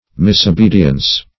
Search Result for " misobedience" : The Collaborative International Dictionary of English v.0.48: Misobedience \Mis`o*be"di*ence\, n. Mistaken obedience; disobedience.